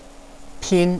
pīn
pin1.wav